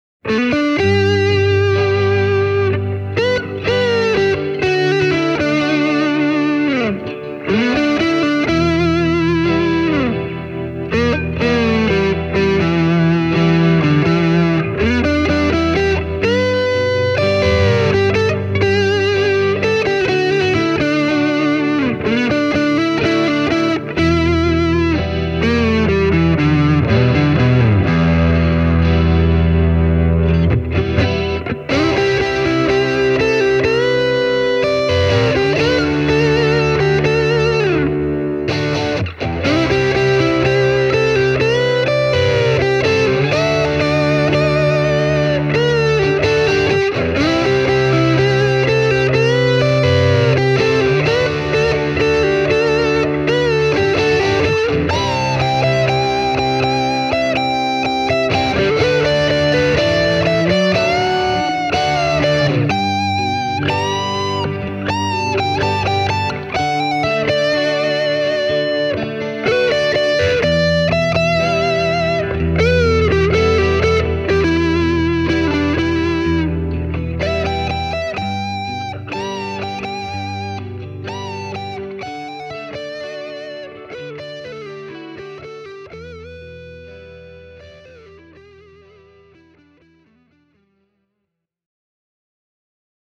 The demo track was recorded using an analogue chorus pedal, a tube screamer-type overdrive, as well as the amp’s built-in reverb and tremolo.
The rhythm parts were played on a Fender Stratocaster, while the lead was played on a Hamer USA Studio Custom: